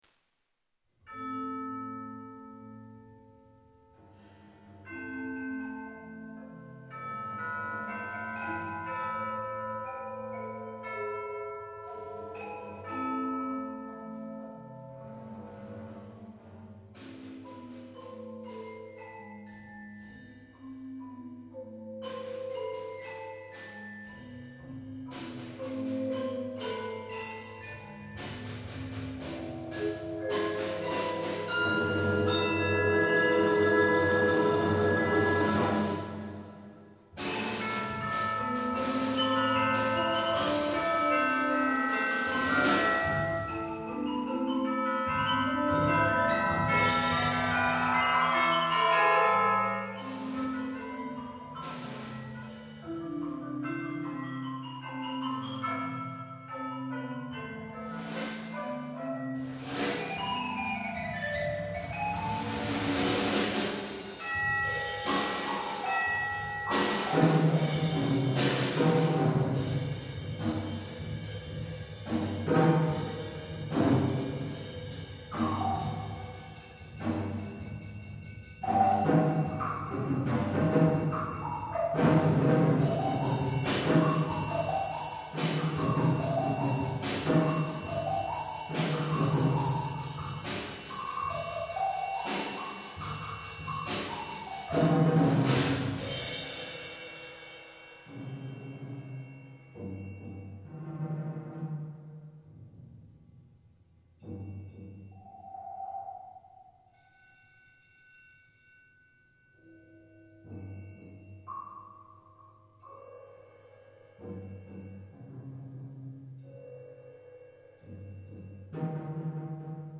Genre: Percussion Ensemble
should sound like six percussionists playing one drumset.
Percussion 1 (chimes, bells, triangle)
Percussion 2 (xylophone, high tom)
Percussion 3 (vibraphone, temple blocks)
Percussion 4 (marimba (4.3 octaves), low tom)
Percussion 5 (bass drum, tam-tam)
Percussion 6 (snare drm, low tom, medium suspended cymbal)